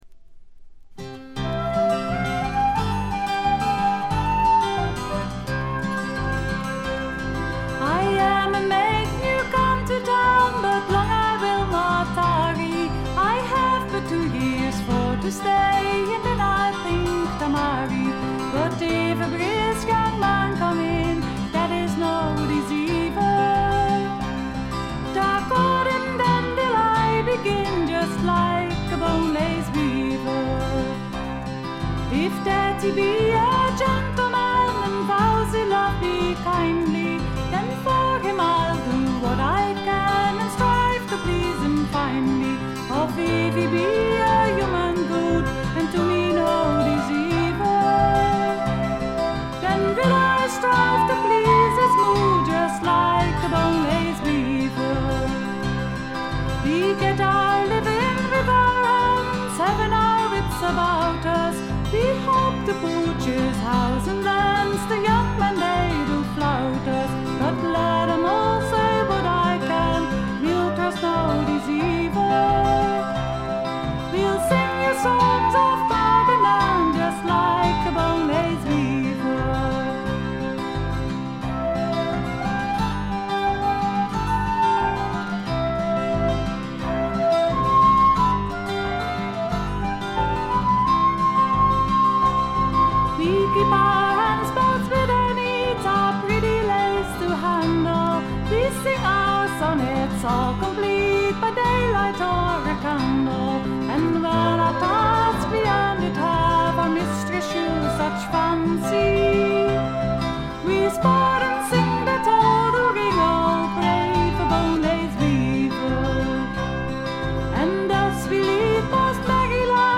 1stの木漏れ日フォーク的な穏やかさ、暖かさを残しながらも、メリハリの利いた演奏と構成で完成度がぐんと上がっています。
ジェントルな男性ヴォーカル、可憐で美しい女性ヴォーカル（ソロパートが少ないのがちょっと不満）、見事なコーラスワーク。
Acoustic Guitar, Vocals
Accordion
Bass Guitar
Flute
Oboe
Violin